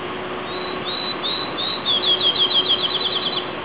The only song sparrow I have heard this year in Perth Amboy is still singing on warm days behind the Fayette Street carton factory.
sparrowfield376.wav